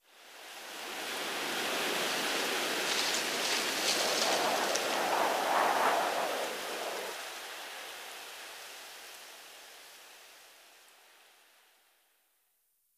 windwhistle3.ogg